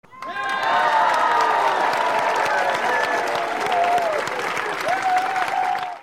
applaus.mp3